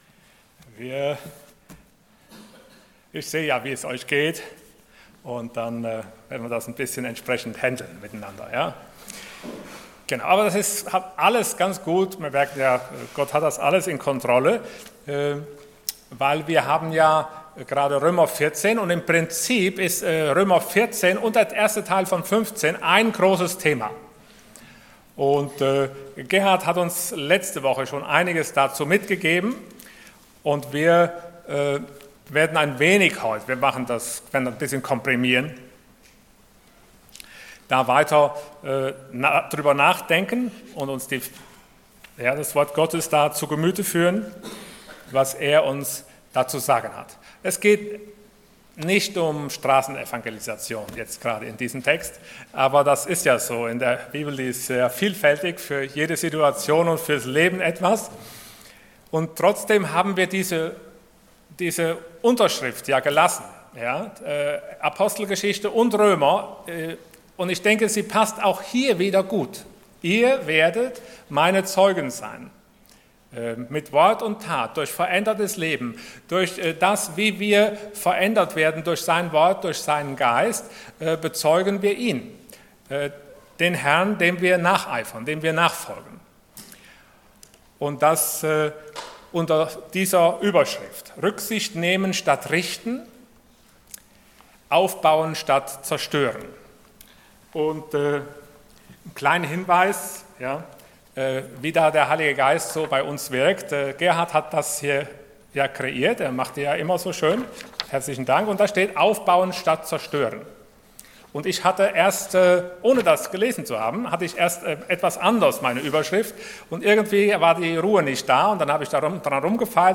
Passage: Römer 14,14-23 Dienstart: Sonntag Morgen